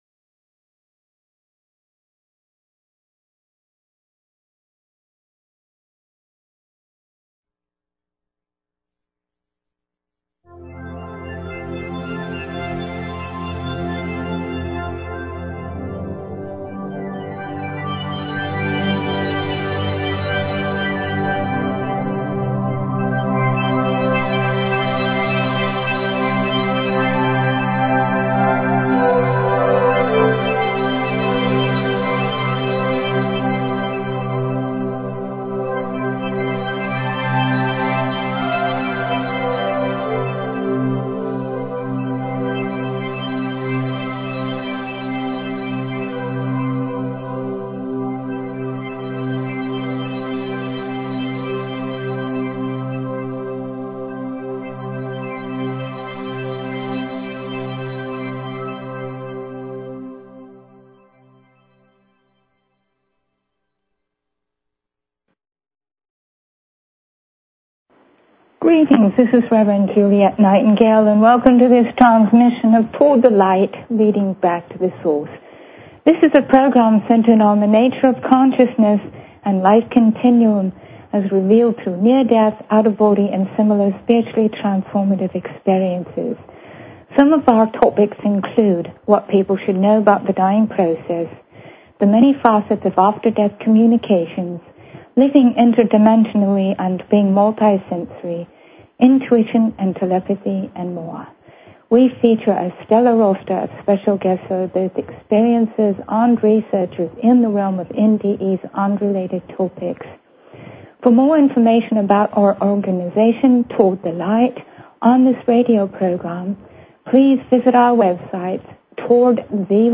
Talk Show Episode, Audio Podcast, Toward_The_Light and Courtesy of BBS Radio on , show guests , about , categorized as
From England, her accent immediately draws people in.